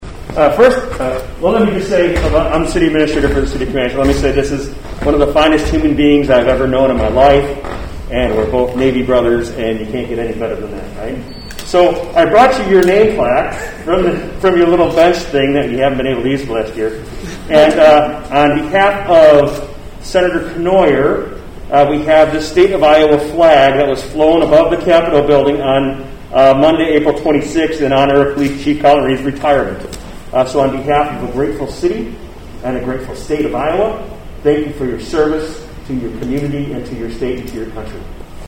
Camanche City Administrator Andrew Kida also praised the retired police chief